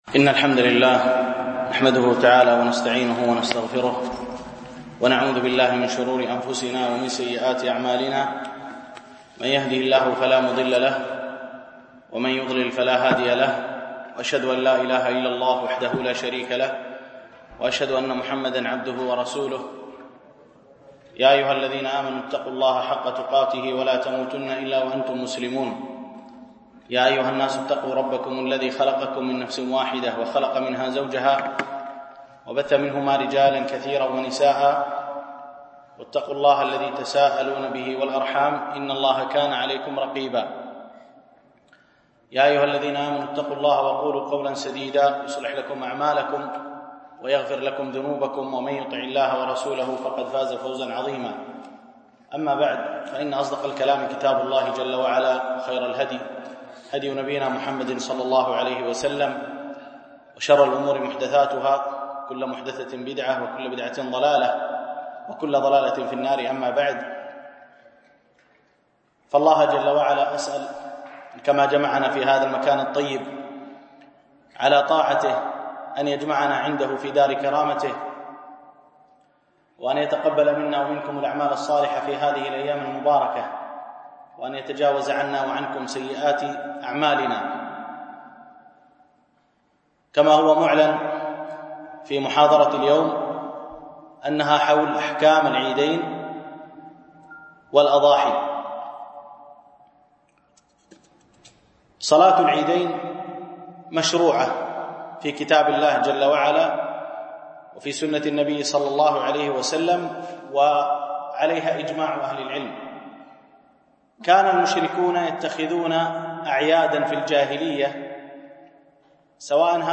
(with translation to english) الألبوم: دروس مسجد عائشة (برعاية مركز رياض الصالحين ـ بدبي)